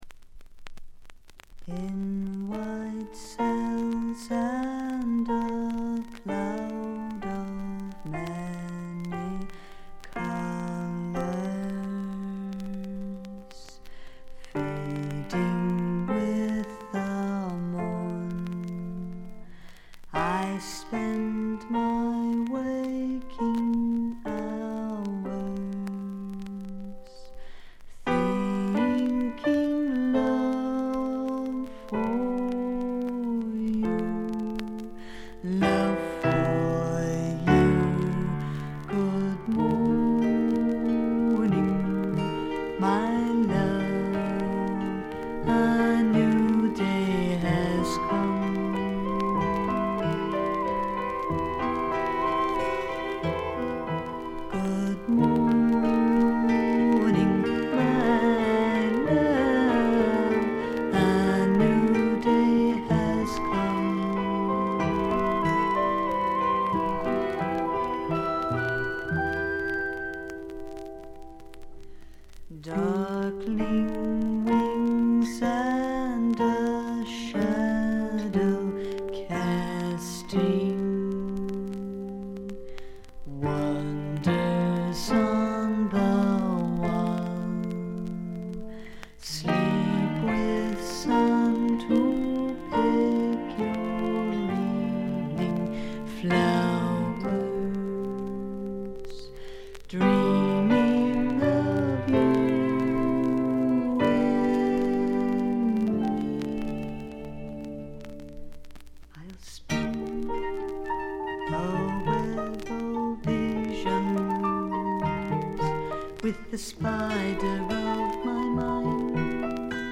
常時大きめのバックグラウンドノイズが出ていますが、特に気になるようなノイズはありません。
スワンプナンバーでは強靭な喉を聴かせますが、アシッド路線では暗く妖艶で怪しいヴォーカルを響かせます。
試聴曲は現品からの取り込み音源です。